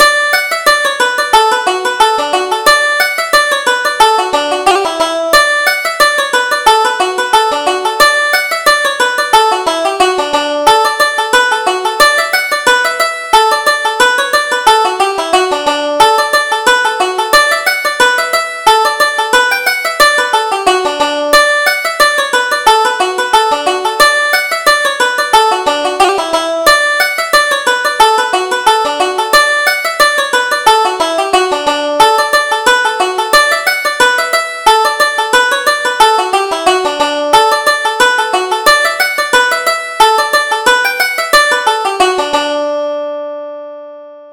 Reel: The Sailor's Cravat